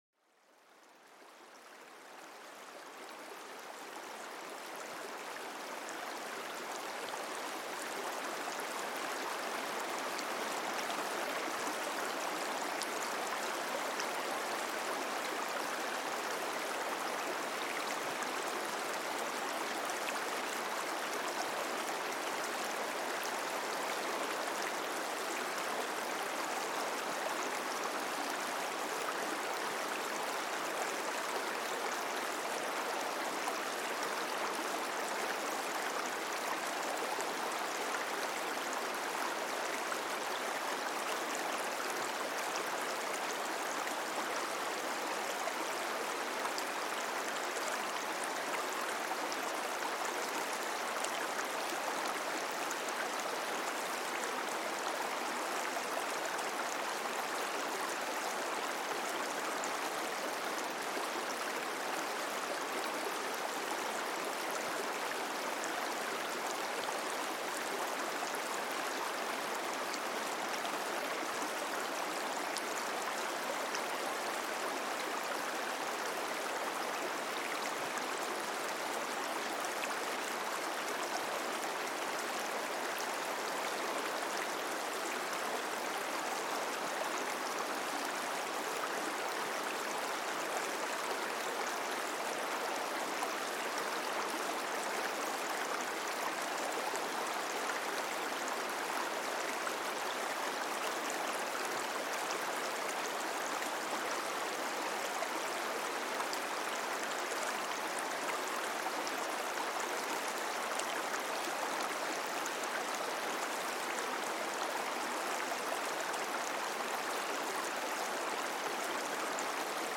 Relájate con el suave murmullo de un río para dormir